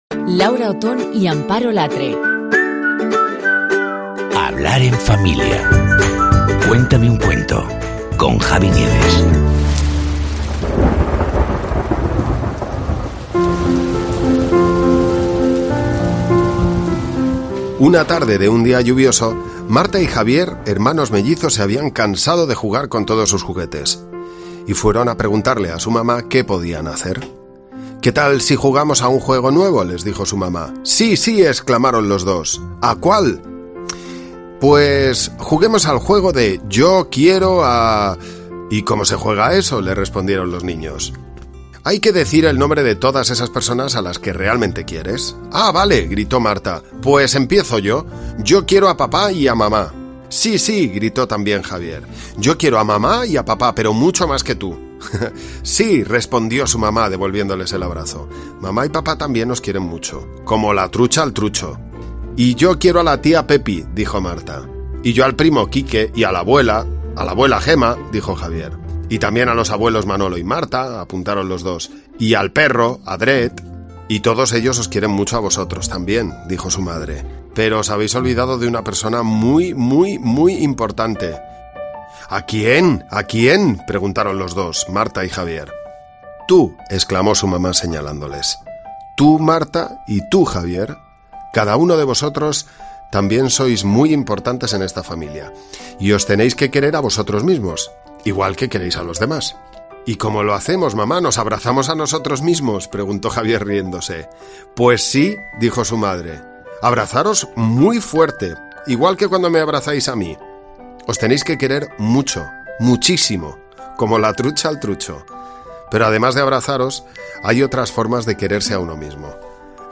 Y le hemos pedido a Javi Nieves, alma pater de Buenos Días Javi y Mar en Cadena 100, que tire de su experiencia como padre y nos cuente un cuento.
'Cuéntame un cuento' con Javi Nieves
Pues bien, hemos pensado que un audiocuento podría ser una buena herramienta para ayudarte.